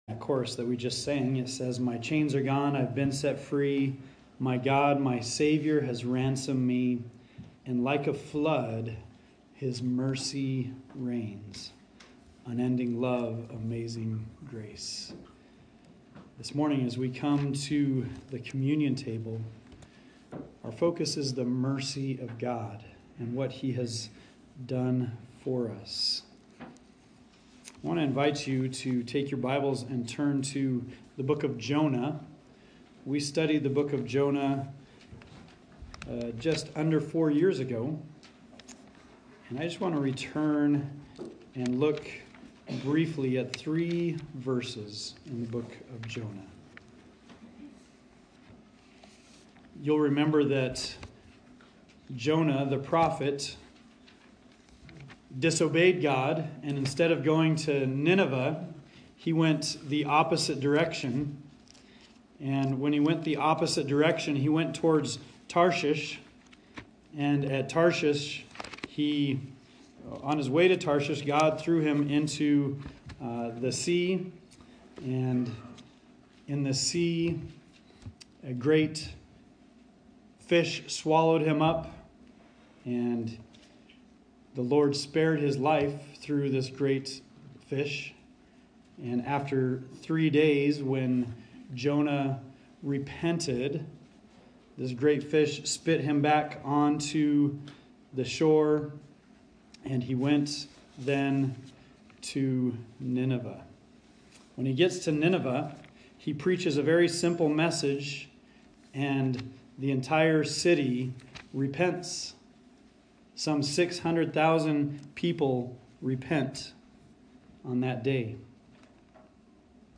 Communion Meditation Passage: Jonah 3:10-4:2